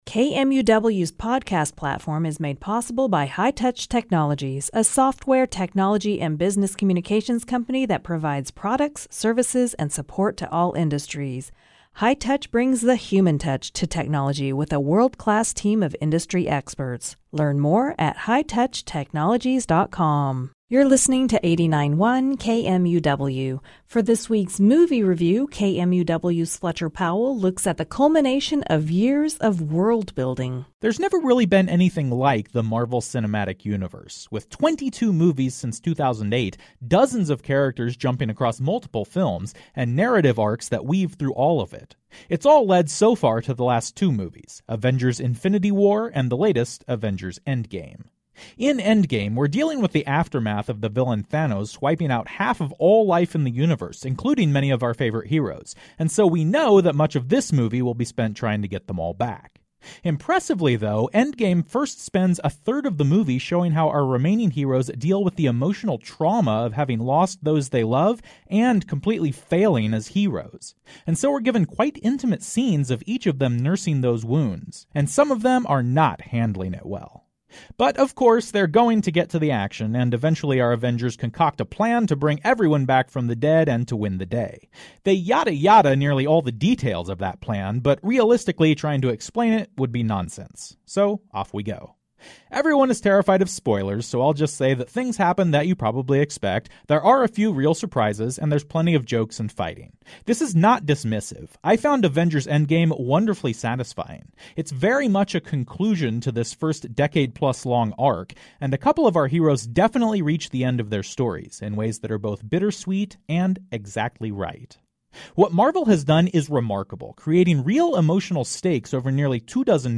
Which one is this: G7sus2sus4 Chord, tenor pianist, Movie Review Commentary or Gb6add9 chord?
Movie Review Commentary